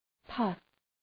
{pʌf}
puff.mp3